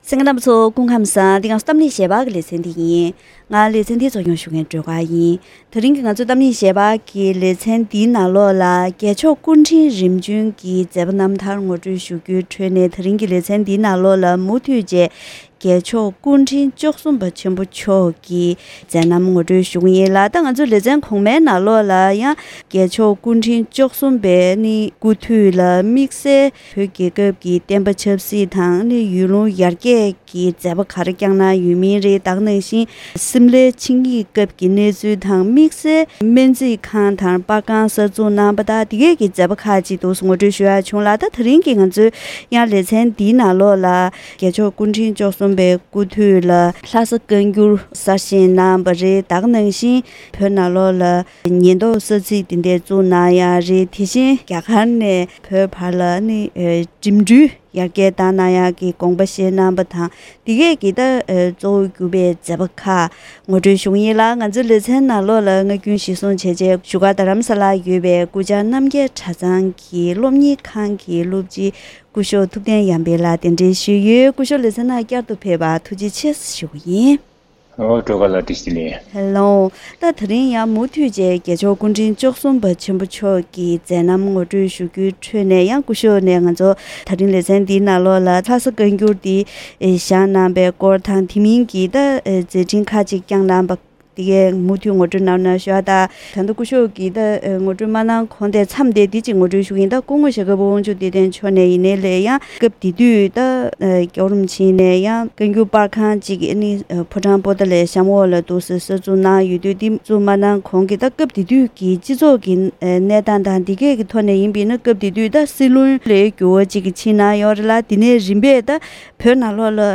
༄༅། །ཐེངས་འདིའི་གཏམ་གླེང་ཞལ་པར་ལེ་ཚན་ནང་༧རྒྱལ་མཆོག་སྐུ་ཕྲེང་རིམ་འབྱོན་གྱི་མཛད་རྣམ་ངོ་སྤྲོད་ཞུ་རྒྱུའི་ཁྲོད་ནས་༧རྒྱལ་མཆོག་སྐུ་ཕྲེང་བཅུ་གསུམ་པ་ཐུབ་བསྟན་རྒྱ་མཚོ་མཆོག་དྭགས་པོ་གླང་མདུན་དུ་འཁྲུངས་ཤིང་། དགུང་ལོ་གཉིས་ལ་ཕེབས་སྐབས་ལྷ་སར་གདན་ཞུས་ཀྱིས་གསེར་ཁྲི་མངའ་གསོལ་ཞུས་པ་དང་། དེ་ནས་གསན་བསམ་སློབ་གཉེར་གནང་བ་སོགས་ཀྱི་སྐོར་ངོ་སྤྲོད་ཞུས་པའི་དུམ་བུ་ཁག་རིམ་པས་གསན་རོགས་གནང་།